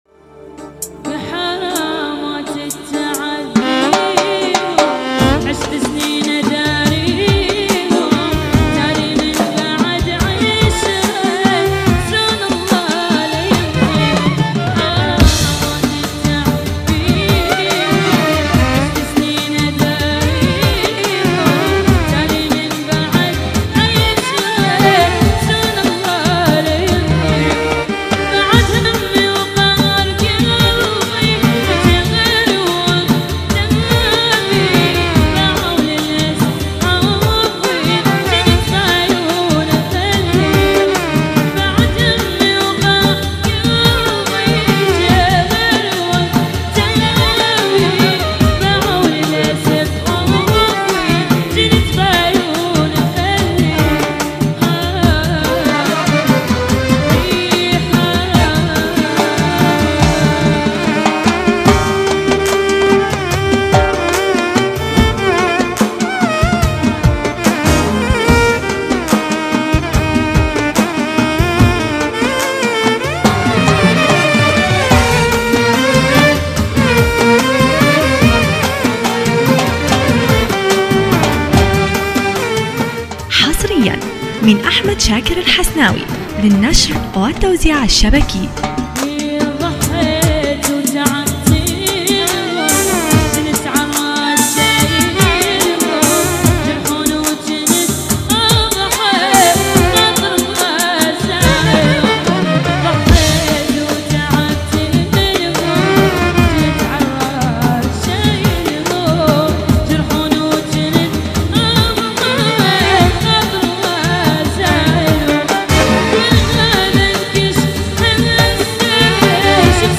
حفلة